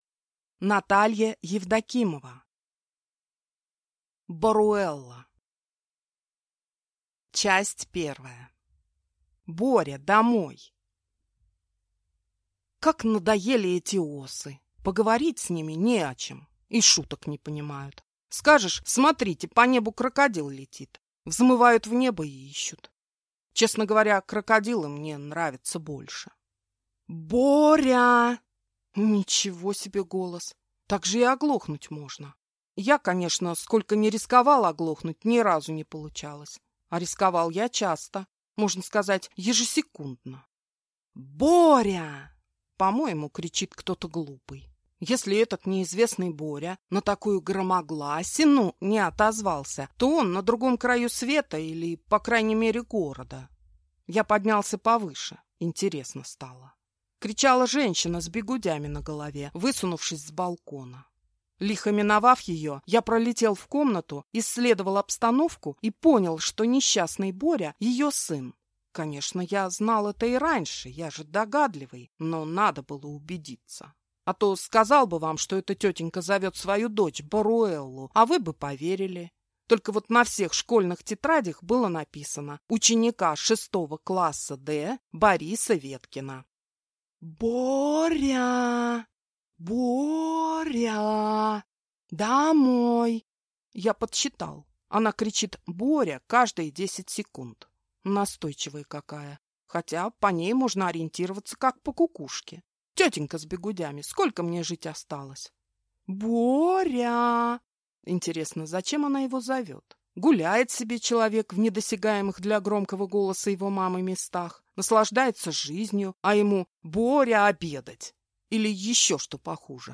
Аудиокнига Боруэлла | Библиотека аудиокниг